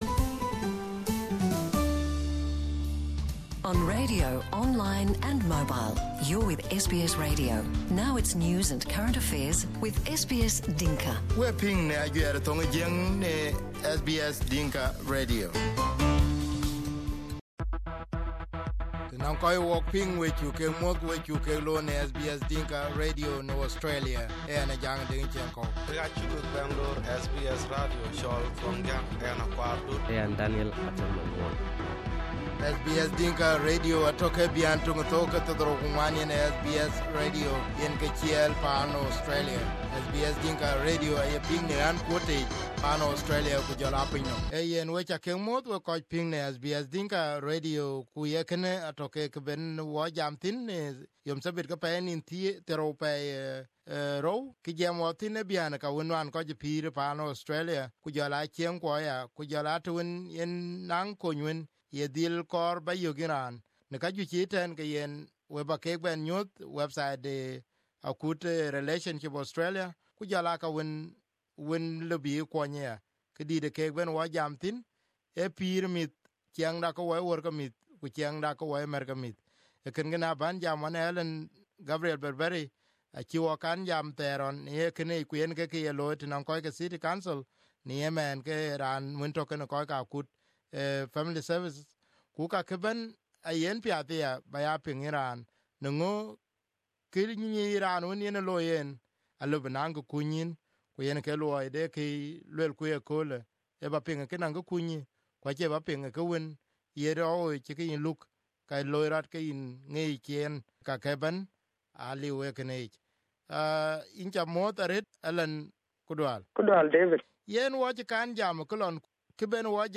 In recent years, the rate of divorce or separation has increased. Children are left as victims when parents fail to understand each other. In this interview